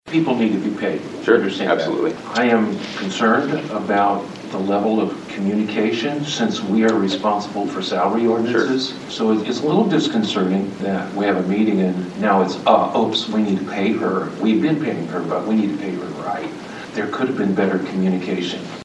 Councilman Harold Gingerich says he has some concerns about the proposal coming at this time.